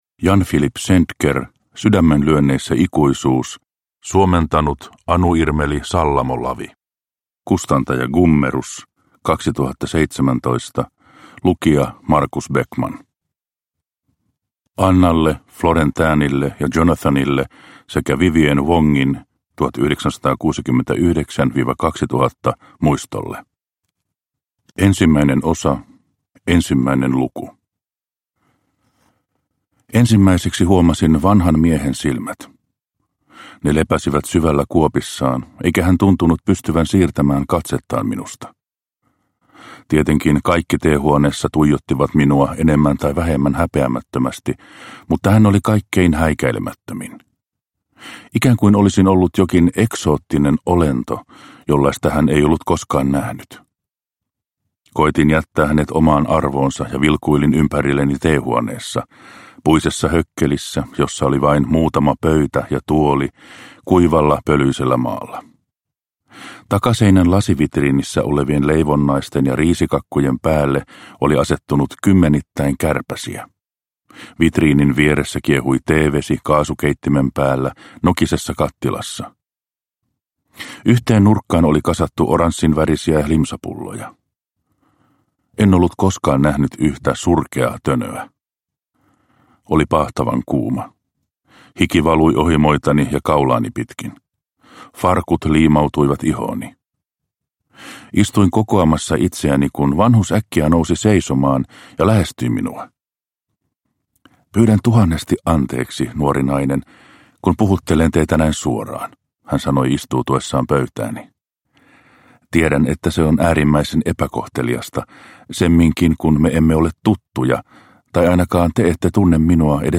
Sydämenlyönneissä ikuisuus – Ljudbok – Laddas ner